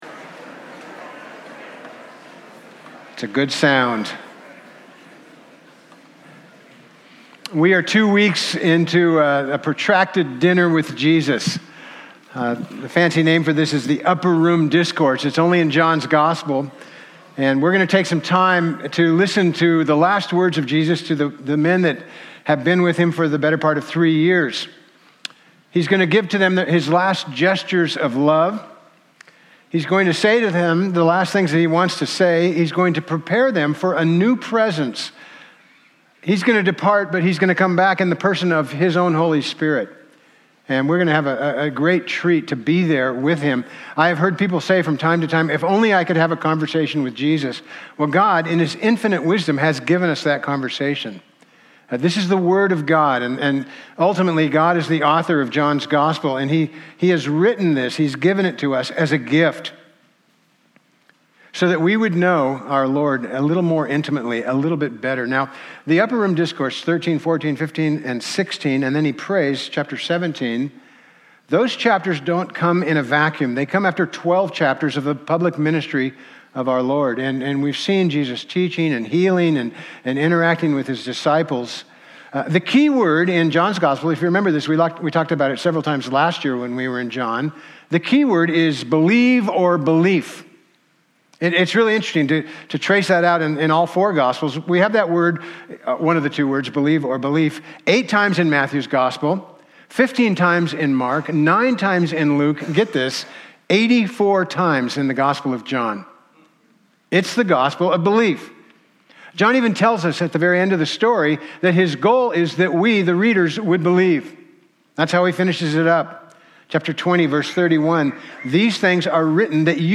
John 13:21-30 Service Type: Sunday Topics